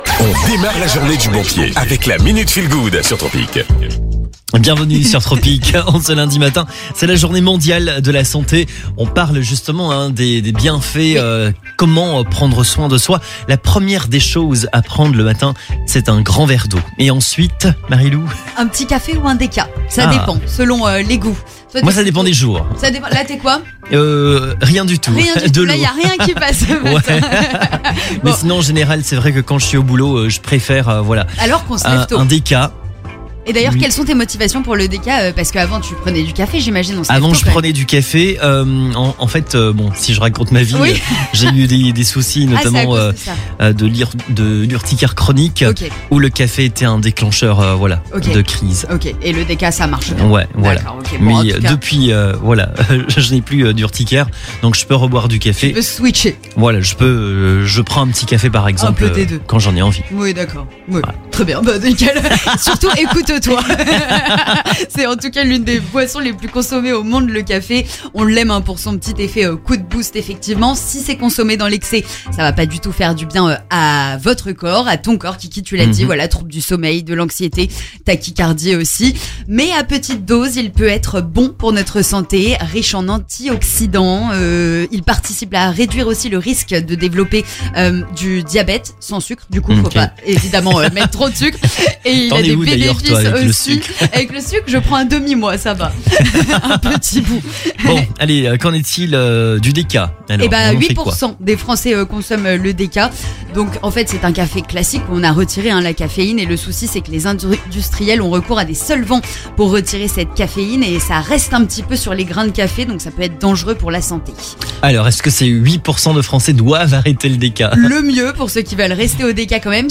Bonne humeur, météo, horoscope, la question du matin, l'escale tropicale, la minute feel good , des fous rires et surtout le maximum de soleil et le meilleur des hits pour demarrer la journée du bon pied !